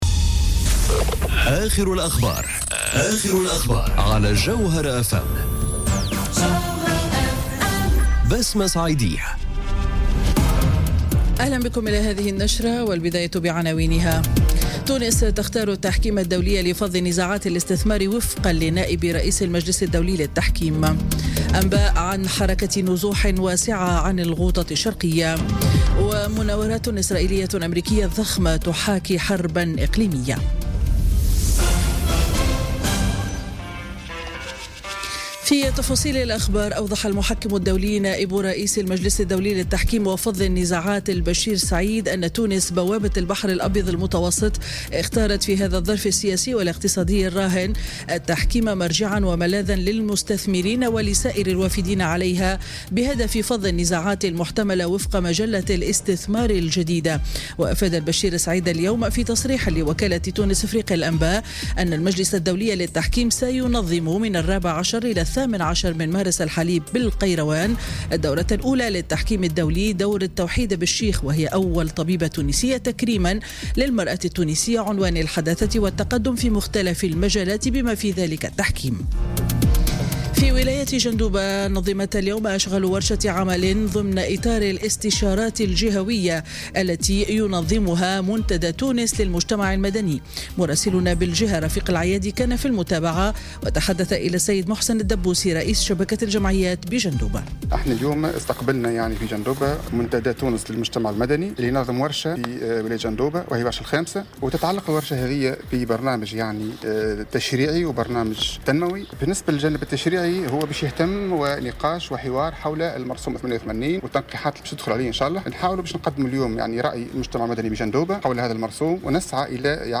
نشرة أخبار منتصف النهار ليوم الأحد 4 مارس 2018